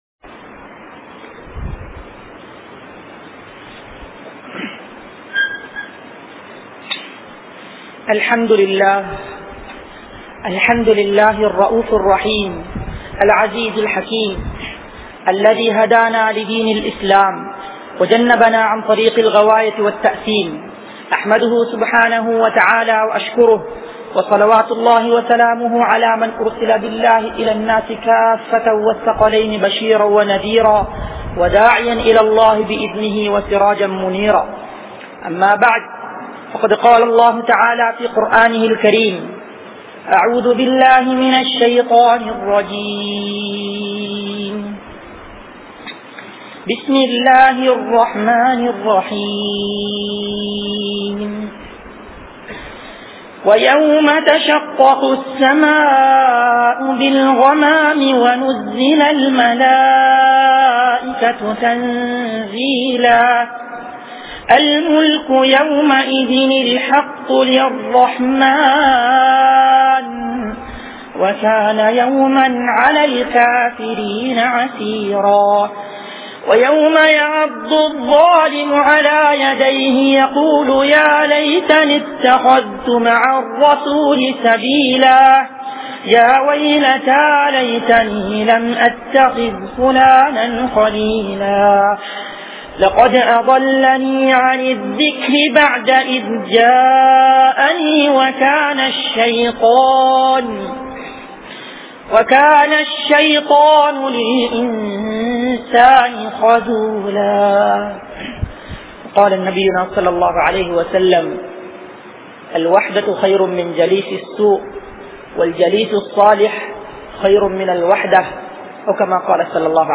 Veettu Soolalai Seri Seiungal | Audio Bayans | All Ceylon Muslim Youth Community | Addalaichenai
Kanampittya Masjithun Noor Jumua Masjith